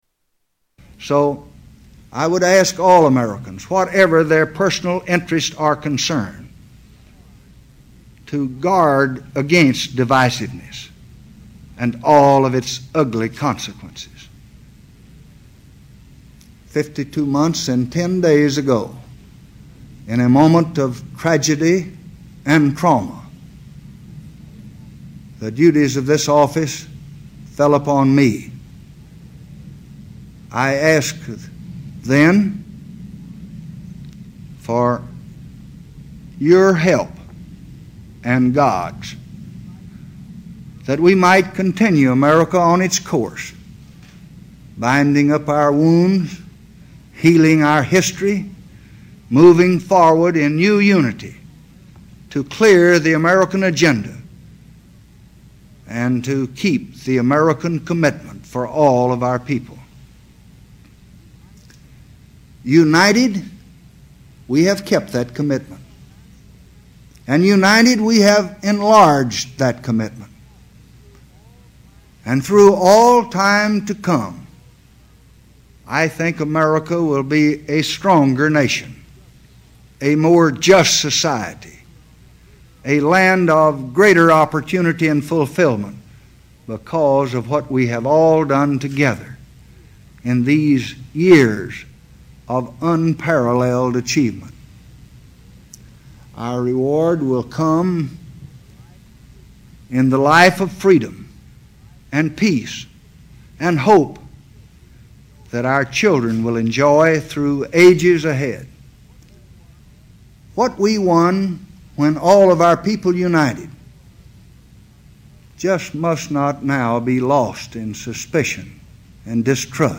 Renunciation Speech Part 7